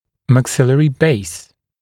[mæk’sɪlərɪ beɪs][мэк’силэри бэйс]основание верхней челюсти